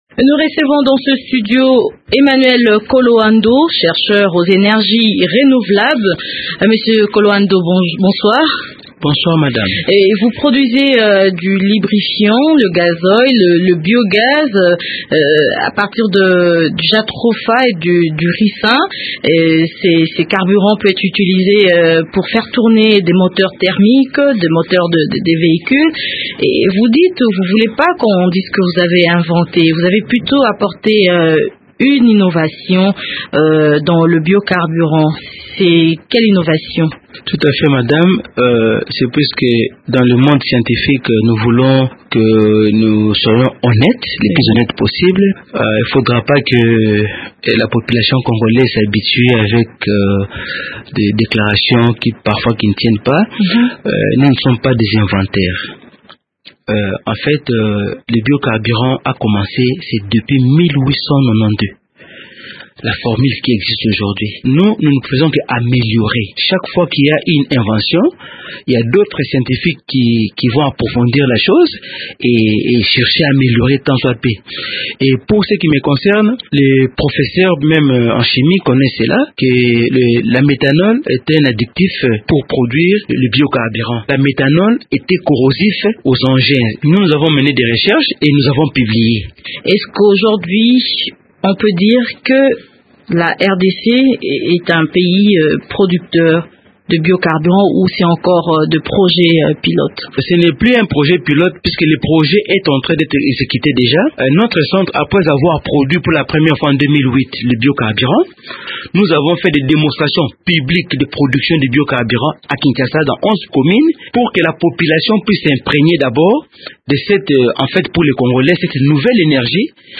entretien-bio-carburant.mp3